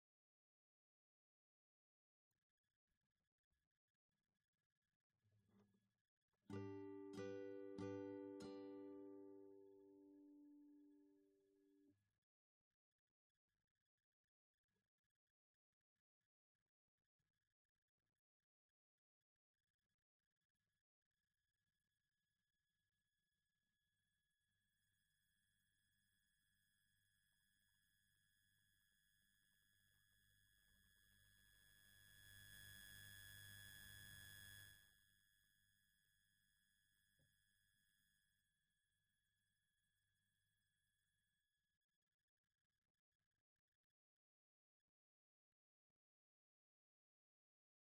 grésil résiduel Ultragain AD8200.mp3
gresil-residuel-ultragain-ad8200.mp3